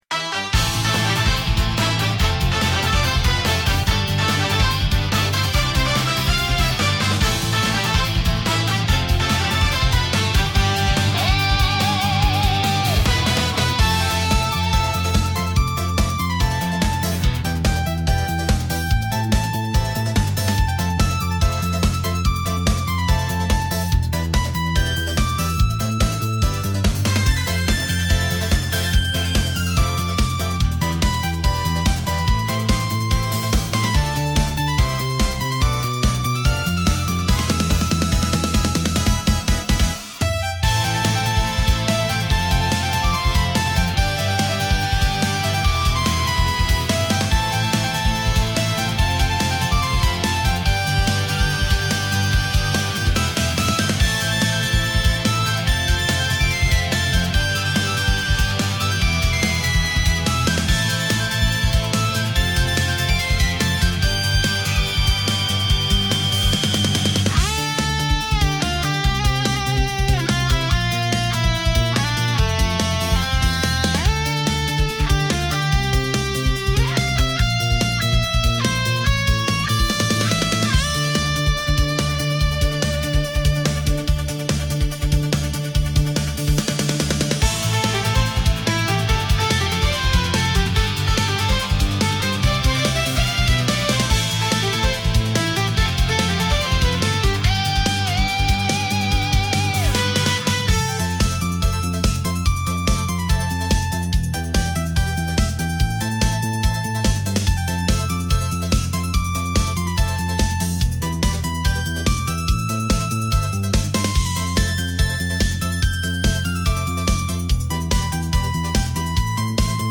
ポップを目指したら、何だか和風っぽくなりました。